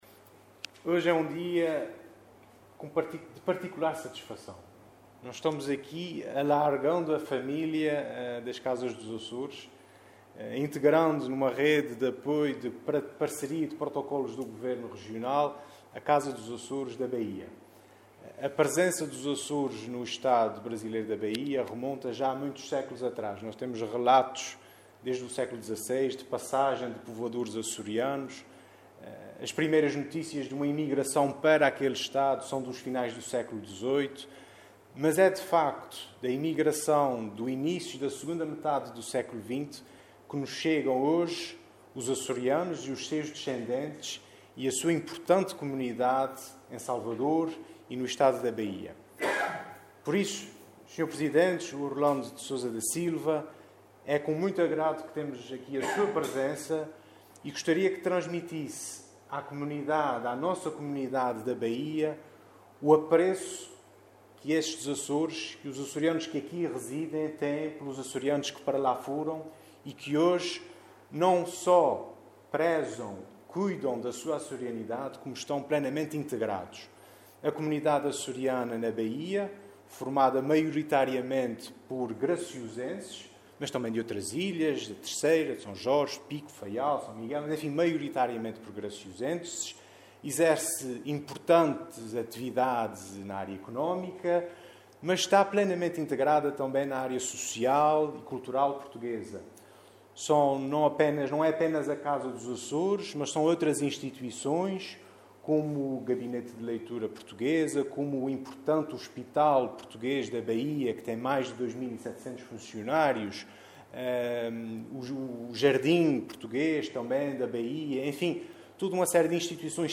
Na cerimónia hoje realizada em Angra do Heroísmo, o Subsecretário Regional da Presidência para as Relações Externas, Rodrigo Oliveira, enalteceu o “relevante papel” que os emigrantes açorianos e seus descendentes continuam a exercer no Estado da Baía.